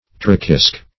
Trochisk \Tro"chisk\, n.